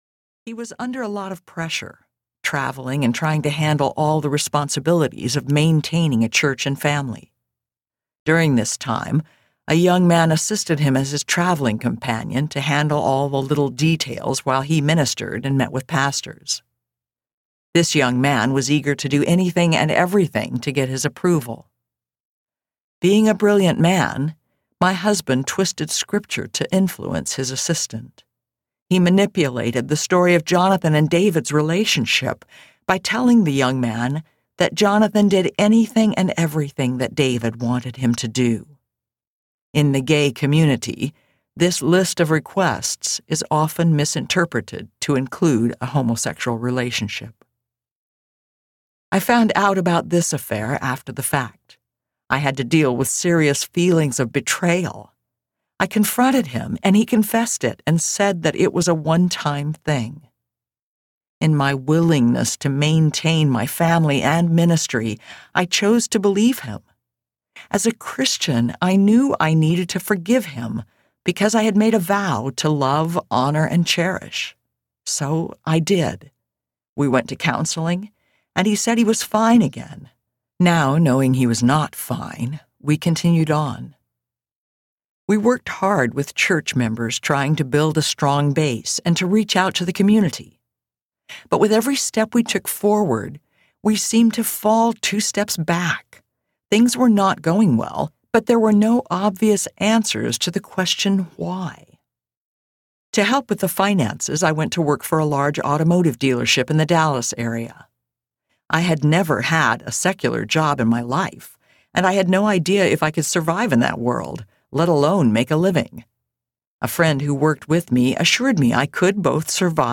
Healing the Heart Audiobook
Narrator
5.37 Hrs. – Unabridged